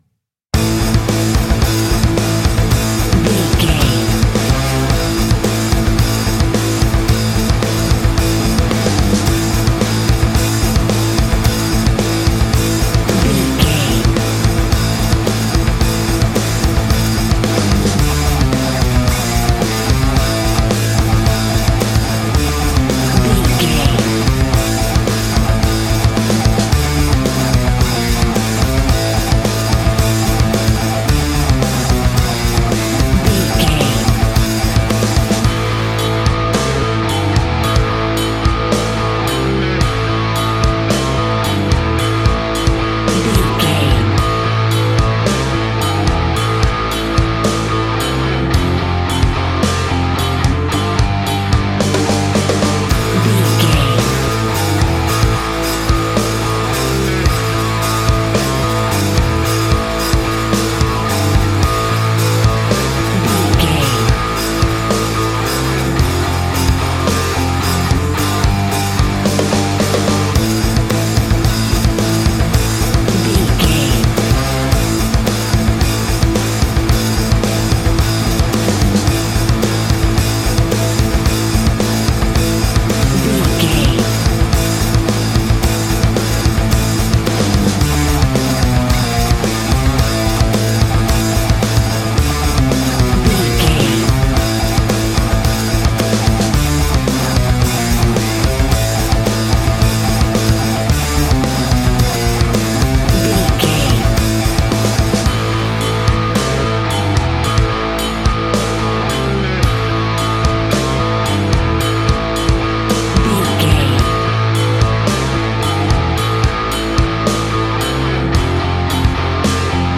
Aeolian/Minor
powerful
electric guitar
bass guitar
drums
organ